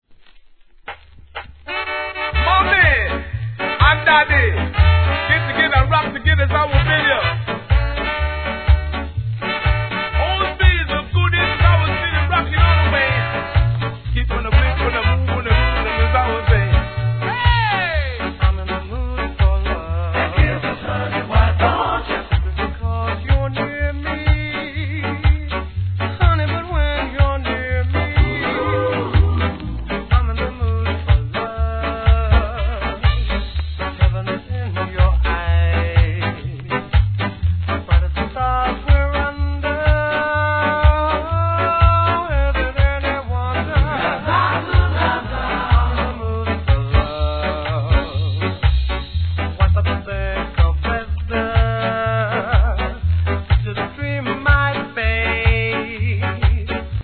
REGGAE
紳士的なMOODがたまらないSKAナンバー!!